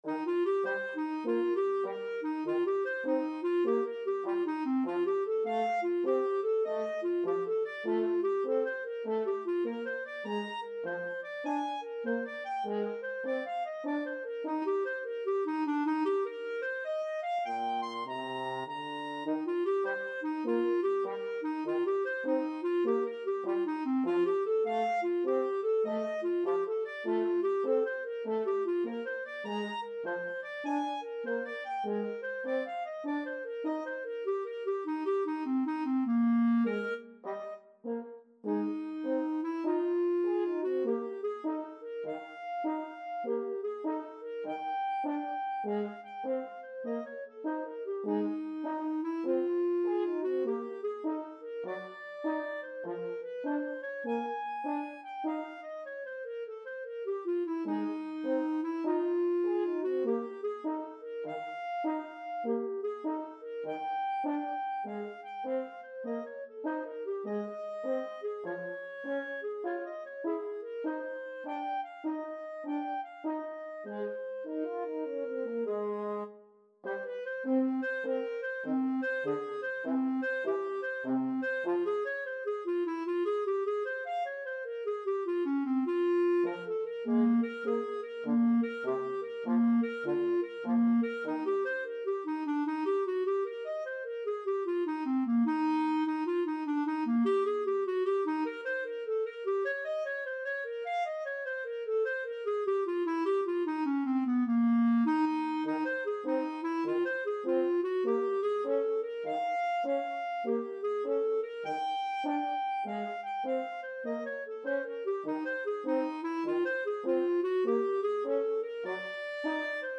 ClarinetFrench Horn
Eb major (Sounding Pitch) (View more Eb major Music for Clarinet-French Horn Duet )
Lightly = c. 100
4/4 (View more 4/4 Music)
Jazz (View more Jazz Clarinet-French Horn Duet Music)